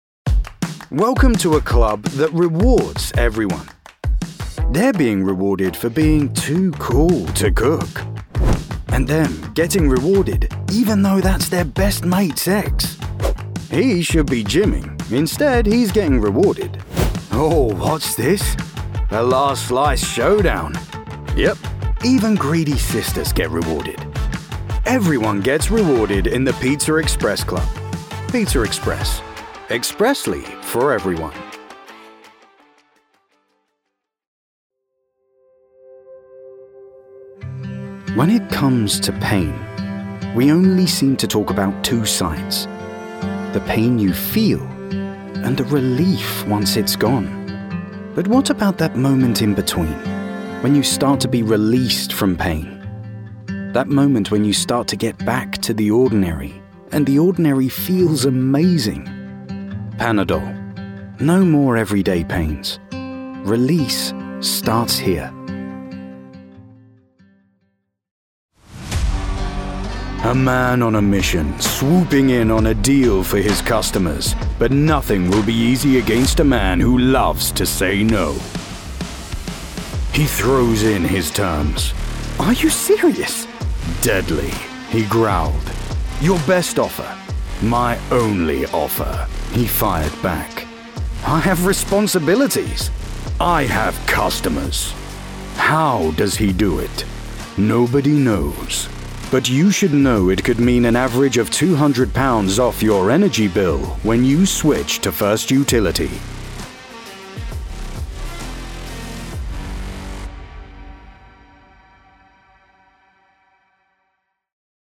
Comercial, Profundo, Natural, Llamativo, Travieso
Comercial